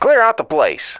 RA2-火箭飞行兵攻击-d.wav